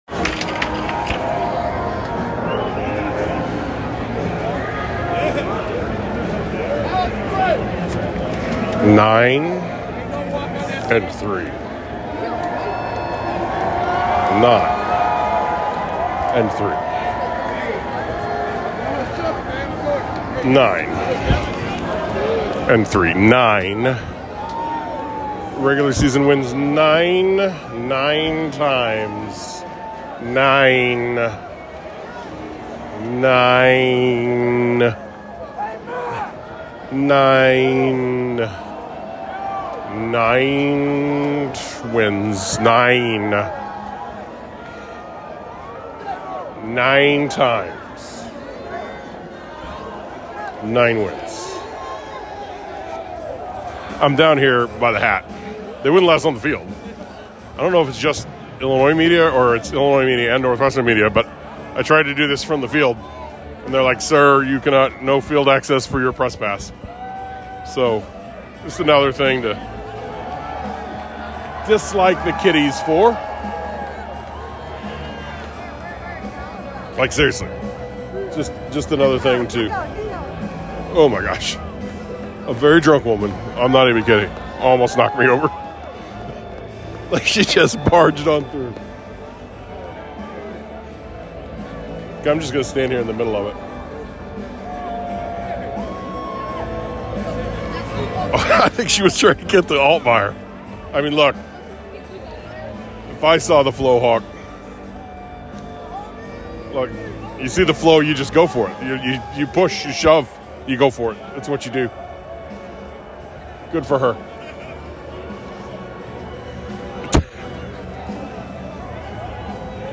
So I went back up in the stands and recorded this as the Illini fans came down to celebrate with the team near the dugout (yes, dugout).